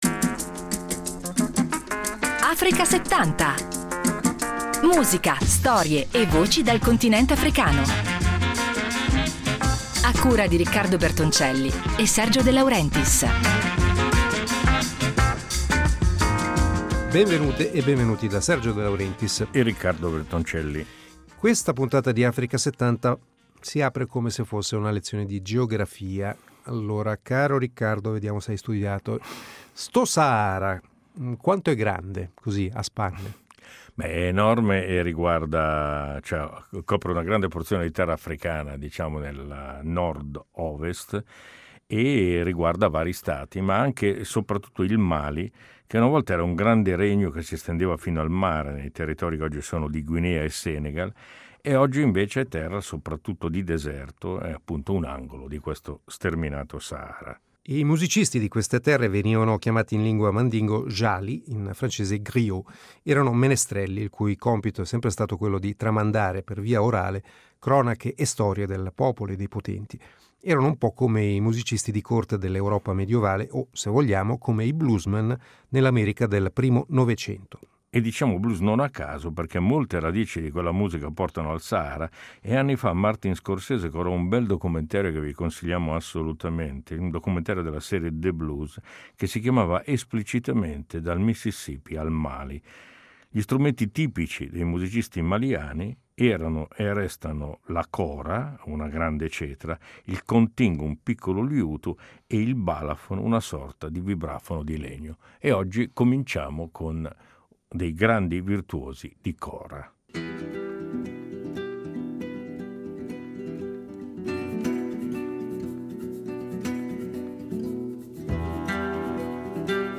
Un viaggio sonoro nel Sahara tra storie, musica e legami nascosti con il blues americano